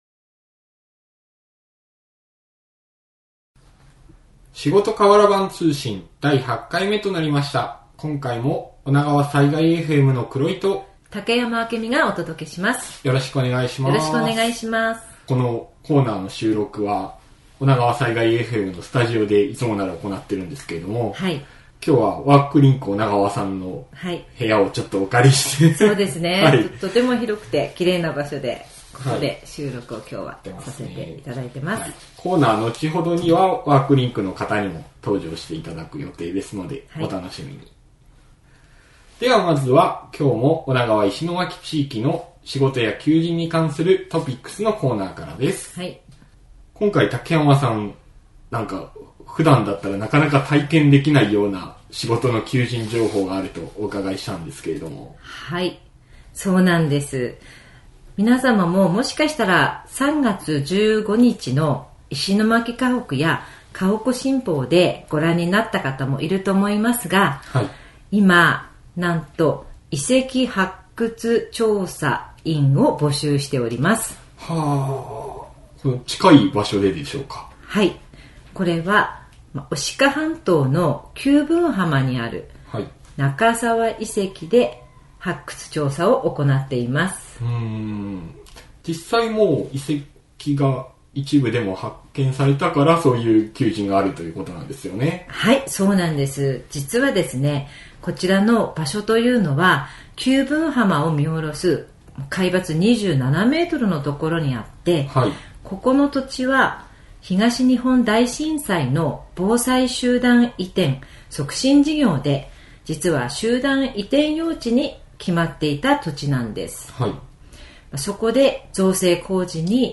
第８回目は、 【トピックス】 ・遺跡発掘調査員募集のお話し? 【企業インタビュー】